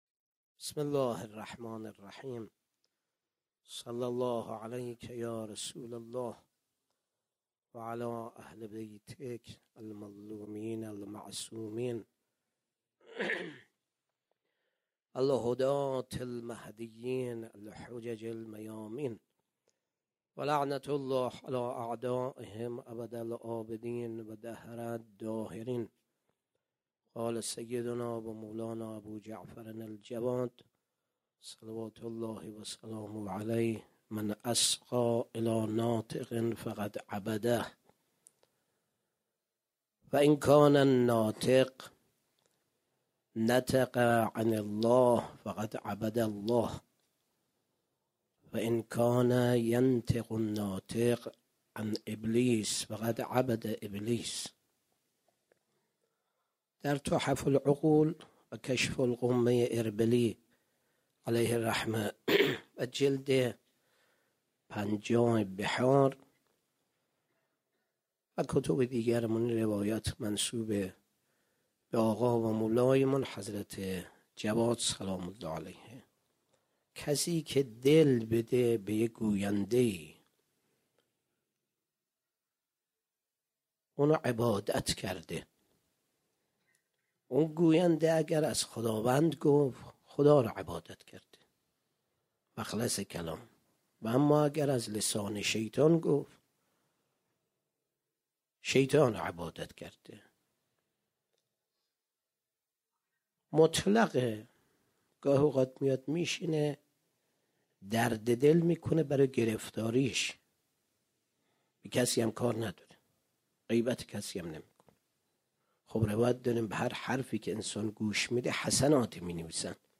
20 مرداد 97 - غمخانه بی بی شهربانو - سخنرانی